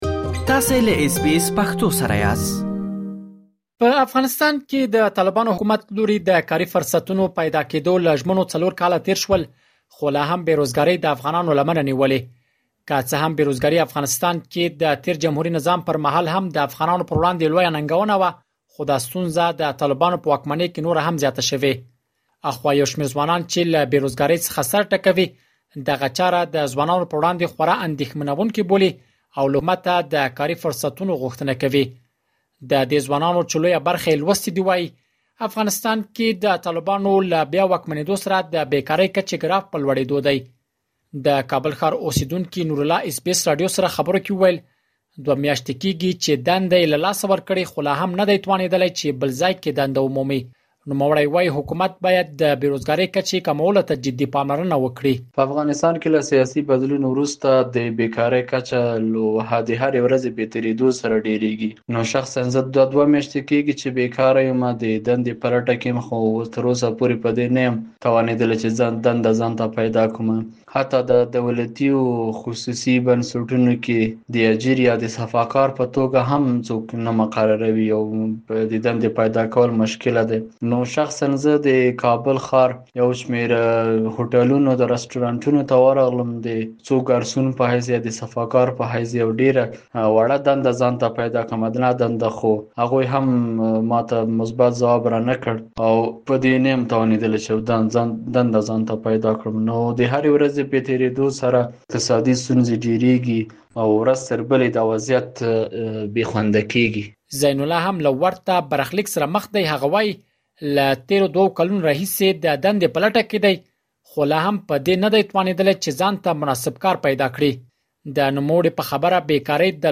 یو شمېر ځوانان چې له بې وزګارۍ څخه سر ټکوي دغه چاره د ځوانانو پر وړاندې خورا اندېښمنونکې بولي او له حکومته د کاري فرصتونو غوښتنه کوي. مهرباني وکړئ لا ډېر معلومات په رپوټ کې واورئ.